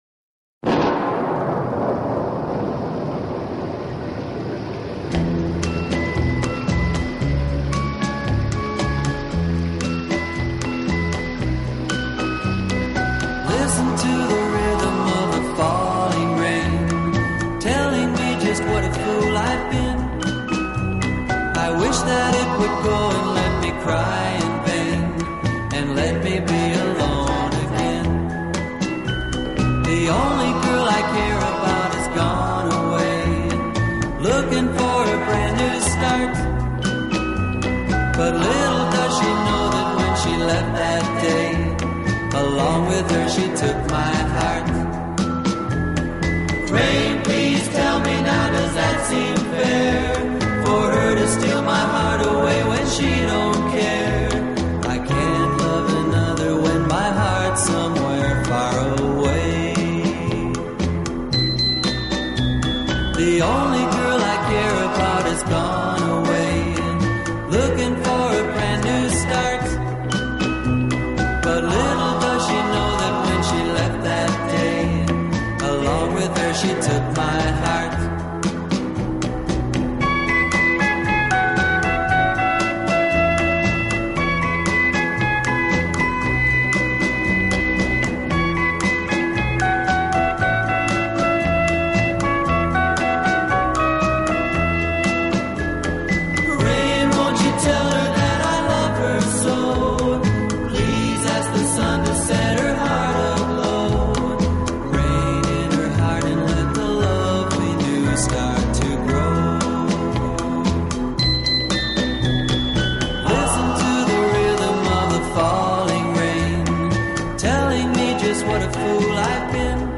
【欧美浪漫】